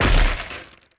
smash.CdwZeYGU.mp3